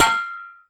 anonDink.ogg